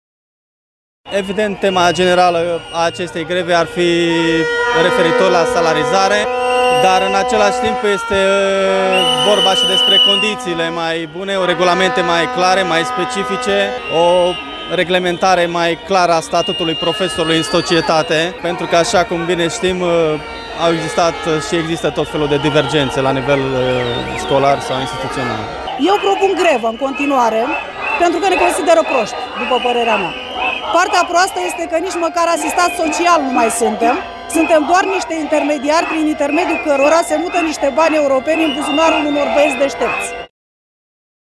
Profesorii au ieșit pentru a doua zi consecutiv să protesteze în fața Prefecturii din Brașov
Peste 400 de dascăli cu pancarte în mâini și-au strigat nemulțumirile, pornind de la cele salariale, la cele legate de condițiile de muncă:
VOXURI-PROFI.mp3